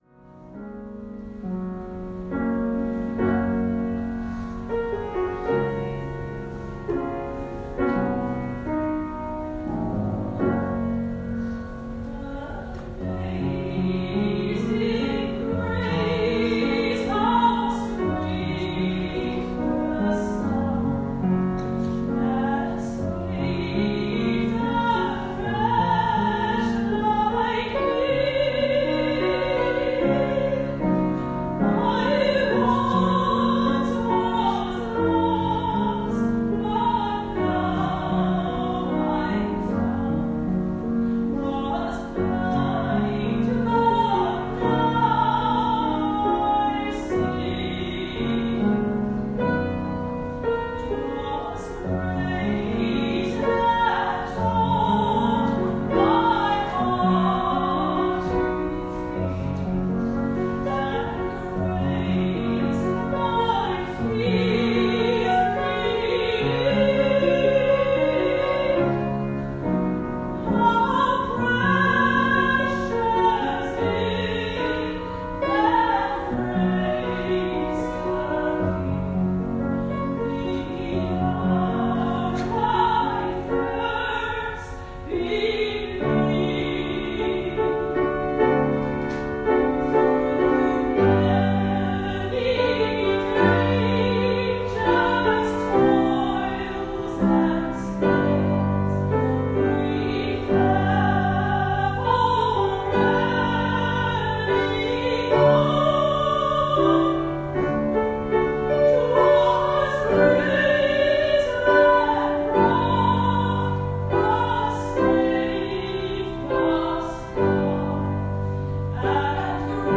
Klassiek